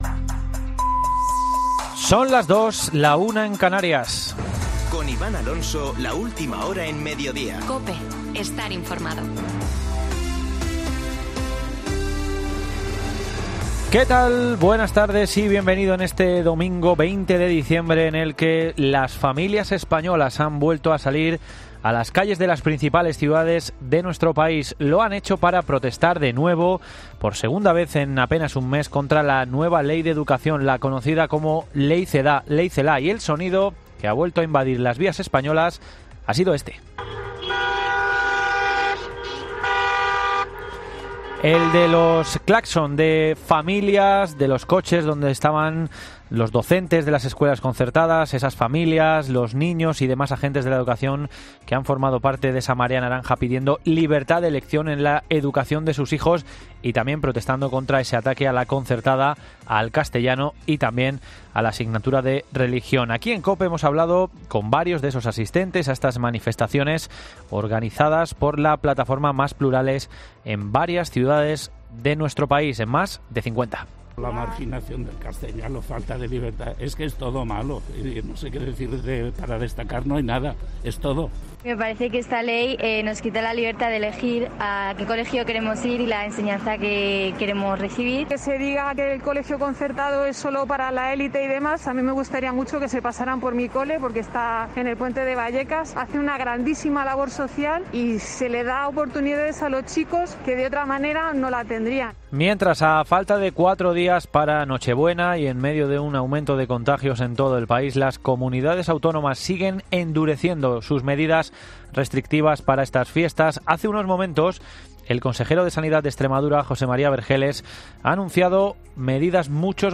Boletín de noticias de COPE del 20 de diciembre de 2020 a las 14.00 horas